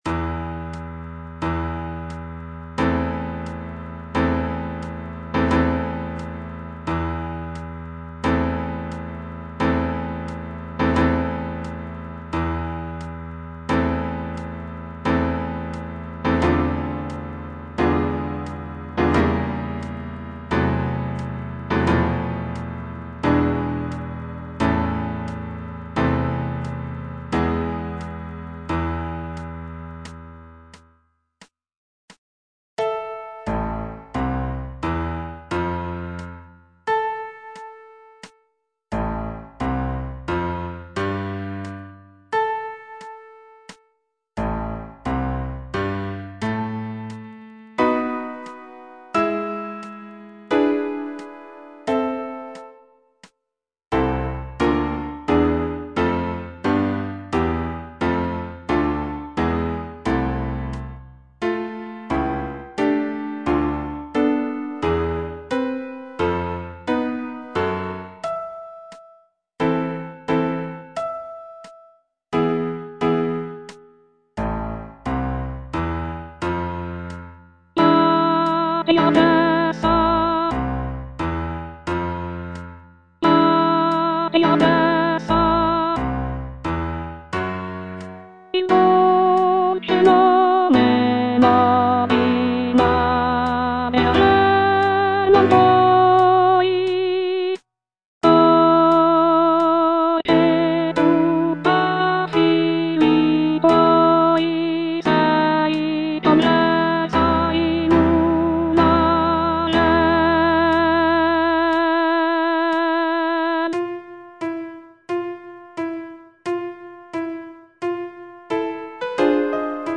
G. VERDI - CORO DI PROFUGHI SCOZZESI FROM "MACBETH" Soprano II (Voice with metronome) Ads stop: auto-stop
The piece features rich harmonies and powerful melodies that evoke a sense of sorrow and longing.